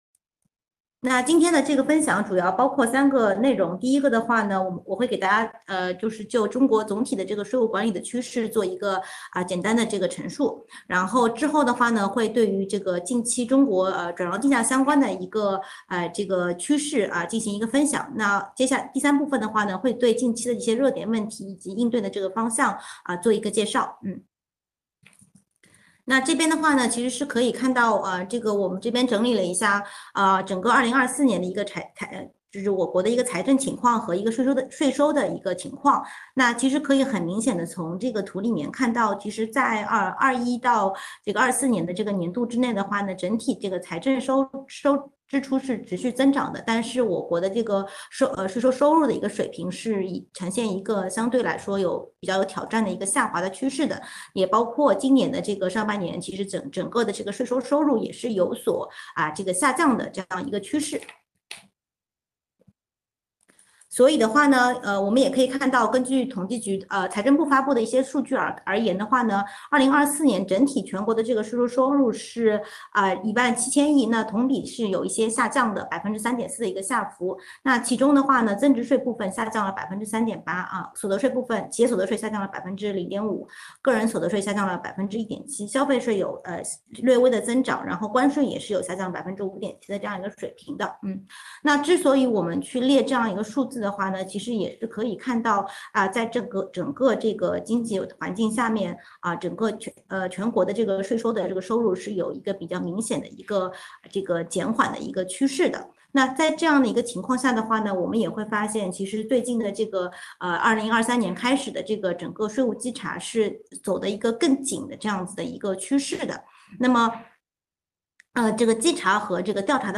视频会议
15：15 互动问答